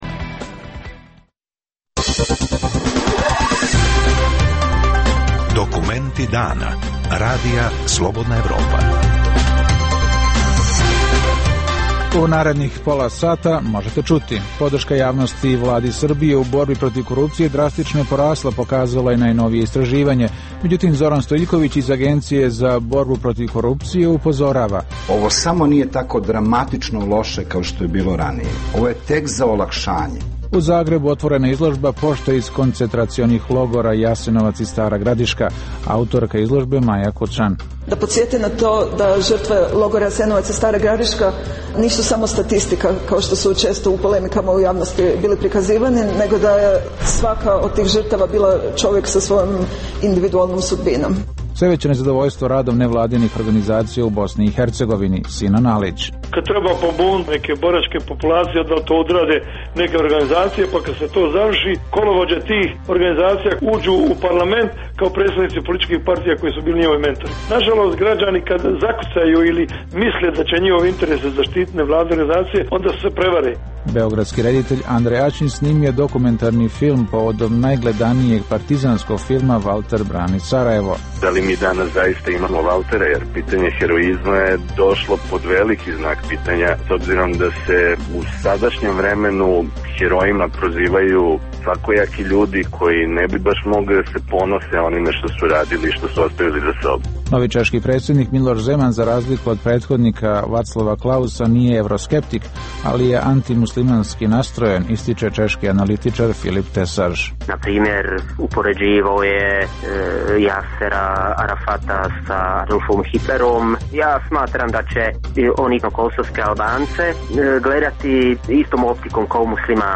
- Intervju sa odlazećim šefom EULEX-a Ksavijerom de Marnjakom. - Drastično porasla podrška javnosti Vladi Srbije u borbi protiv korupcije, pokazuje najnovije istraživanje.